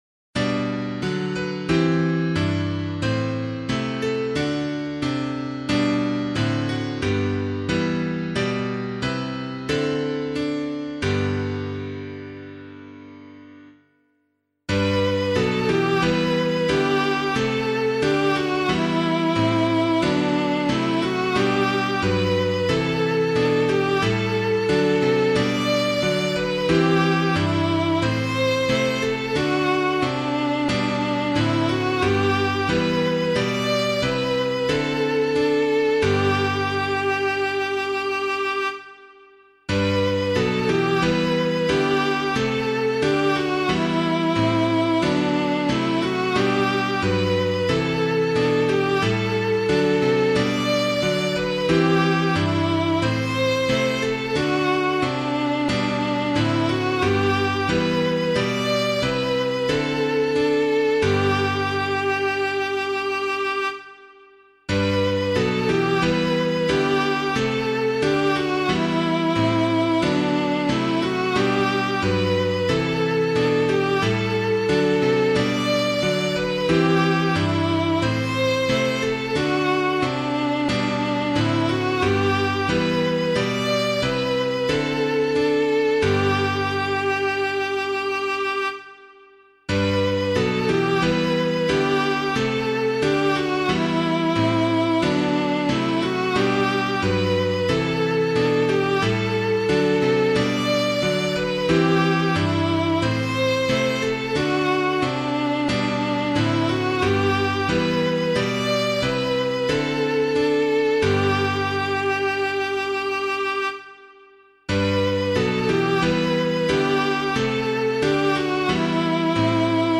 Public domain hymn suitable for Catholic liturgy.